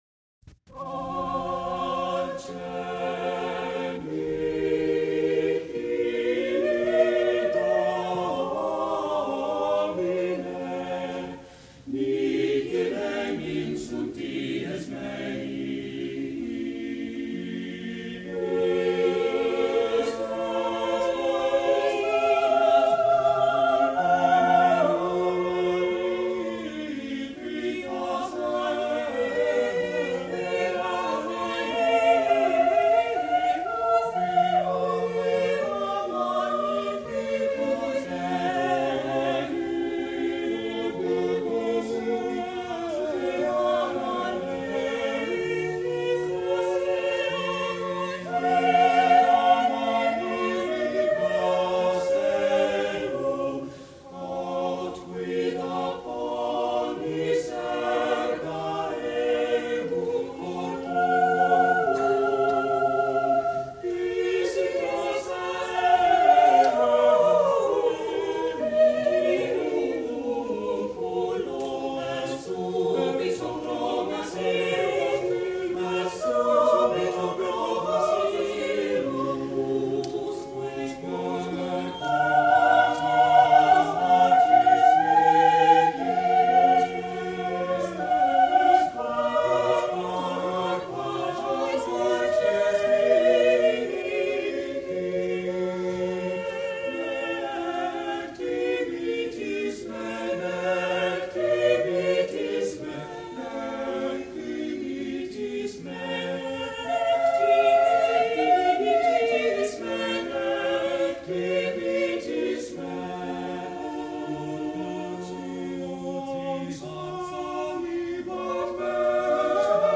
| Vocal Ensemble, St. Agnes, 1972